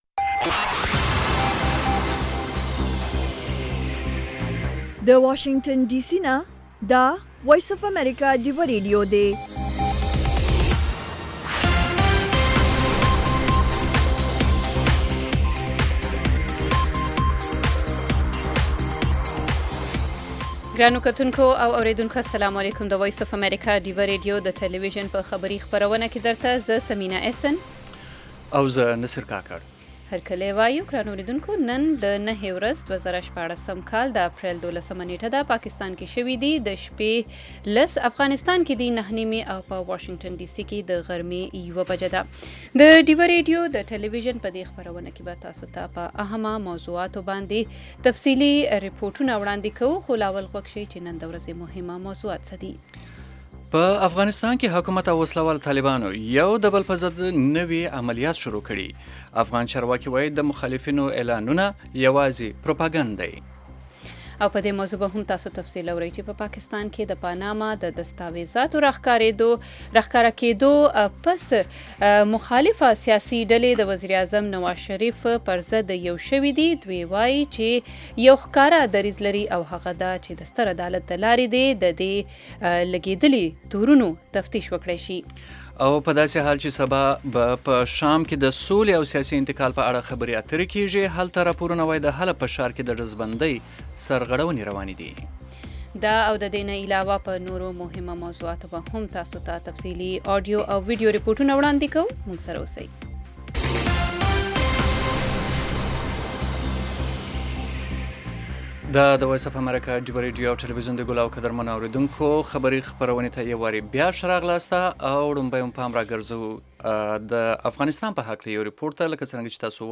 خبرونه
د وی او اې ډيوه راډيو ماښامنۍ خبرونه چالان کړئ اؤ د ورځې د مهمو تازه خبرونو سرليکونه واورئ. په دغه خبرونو کې د نړيوالو، سيمه ايزو اؤمقامى خبرونو هغه مهم اړخونه چې سيمې اؤ پښتنې ټولنې پورې اړه لري شامل دي.